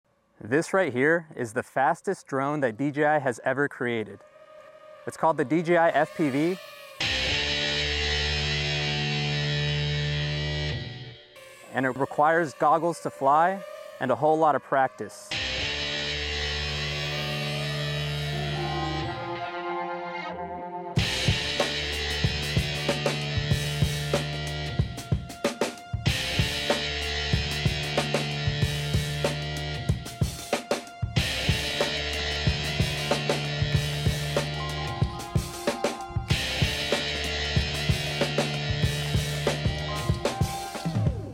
Fastest DJI drone EVER! DJI sound effects free download